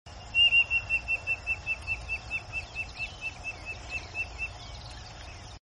CODORNA CANTANDO sound effects free download